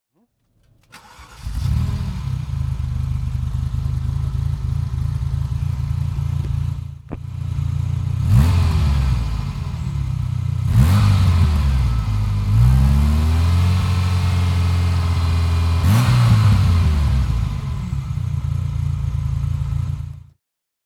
Ferrari Mondial 3.2 (1988) - Starten und Leerlauf